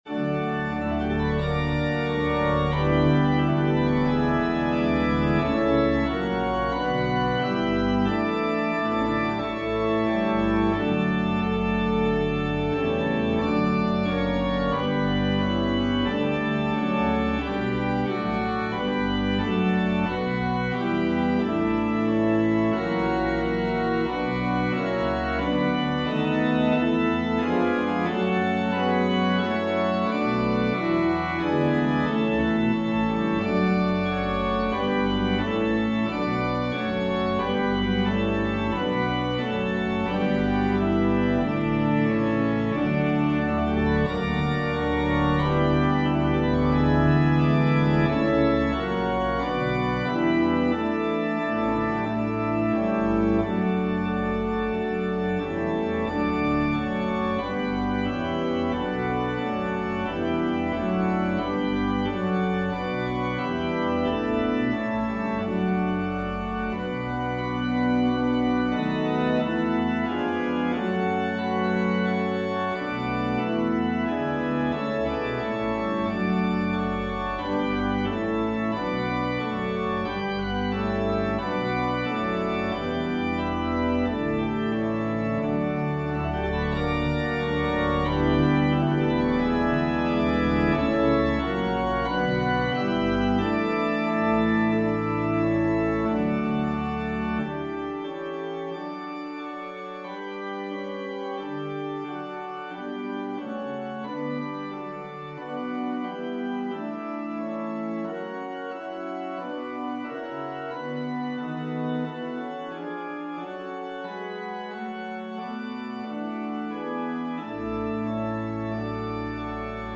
Organ/Organ Accompaniment
Voicing/Instrumentation: Organ/Organ Accompaniment We also have other 2 arrangements of " Praise the Lord with Heart and Voice ".